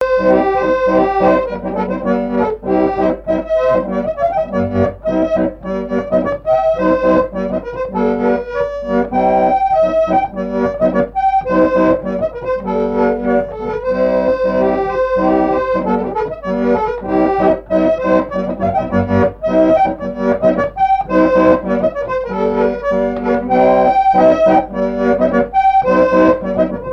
Chants brefs - A danser
scottich sept pas
Pièce musicale inédite